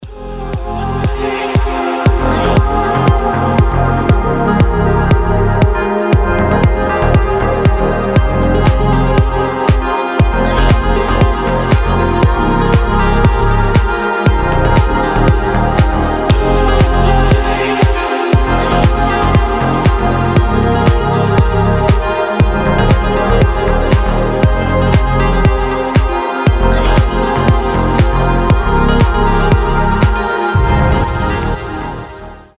• Качество: 320, Stereo
deep house
расслабляющие
космические